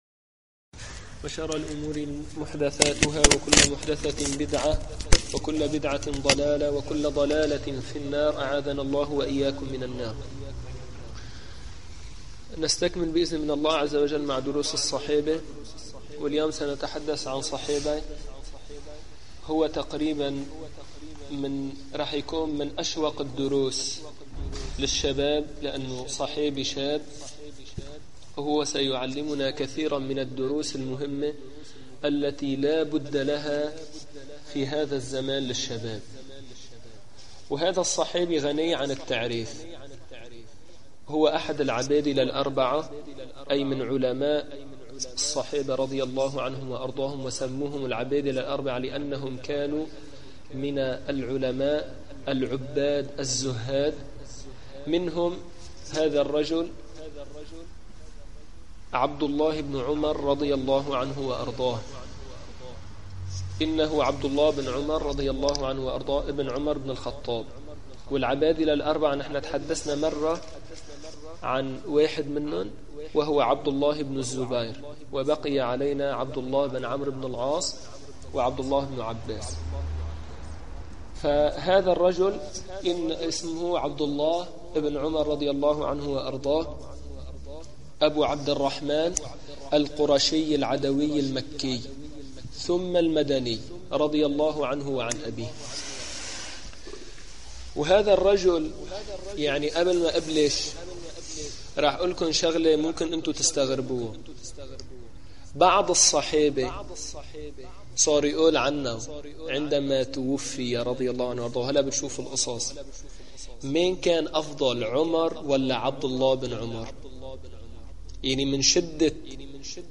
من دروس مسجد القلمون الغربي الشرعية